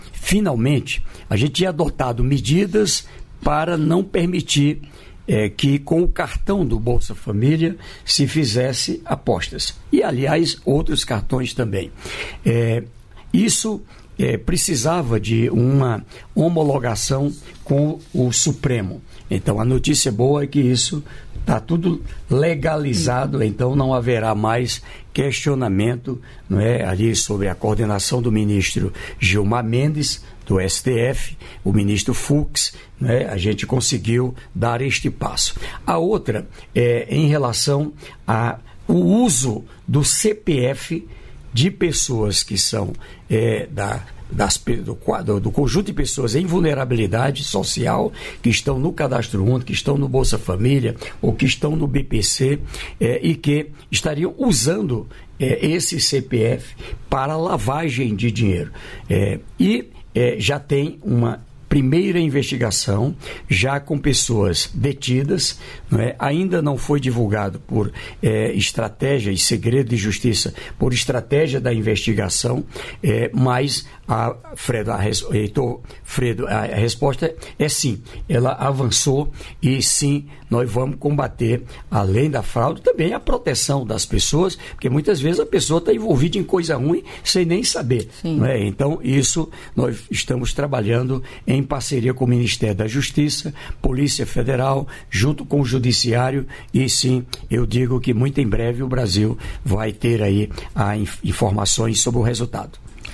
Trecho da participação do ministro do Desenvolvimento e Assistência Social, Família e Combate à Fome, Wellington Dias, no programa "Bom Dia, Ministro" desta quinta-feira (7), nos estúdios da EBC em Brasília (DF).